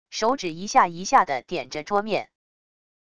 手指一下一下的点着桌面wav音频